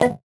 Index of /musicradar/8-bit-bonanza-samples/VocoBit Hits